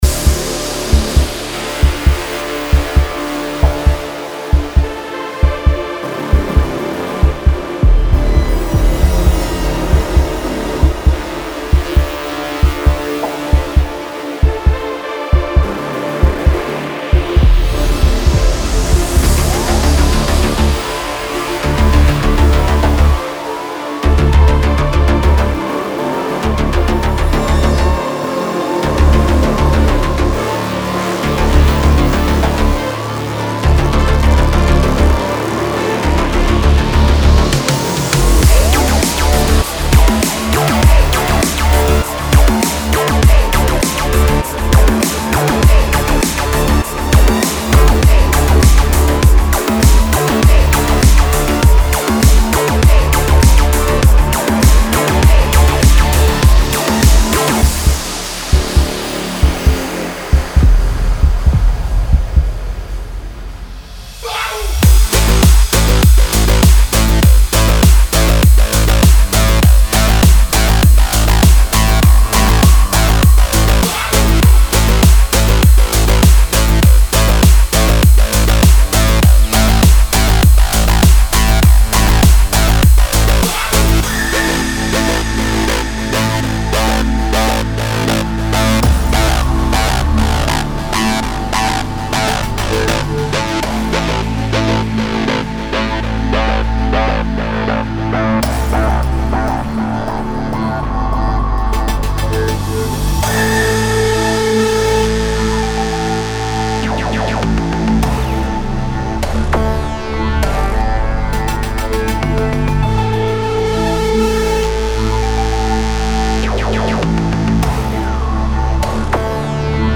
· 16 Acids
· 8 Arps
· 32 Basses
· 18 Leads
· 15 Pads